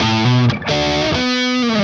Index of /musicradar/80s-heat-samples/130bpm
AM_HeroGuitar_130-B02.wav